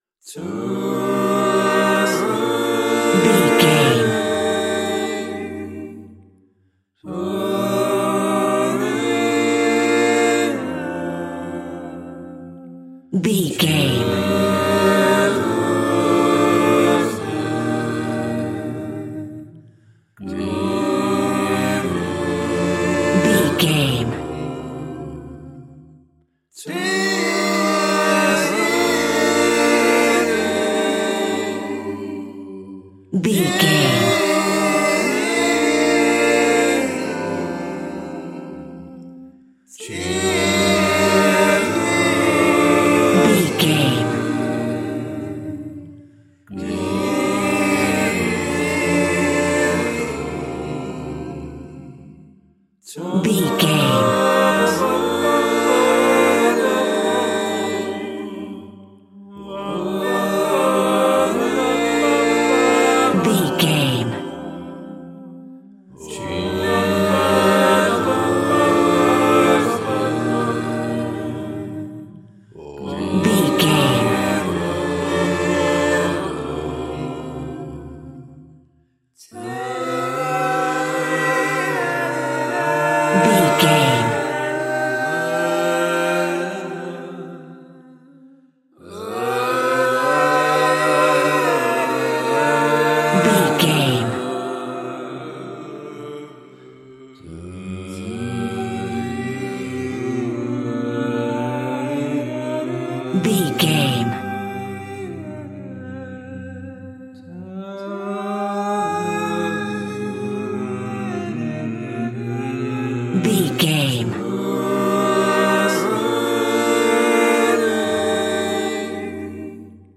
Aeolian/Minor
groovy
inspirational
vocal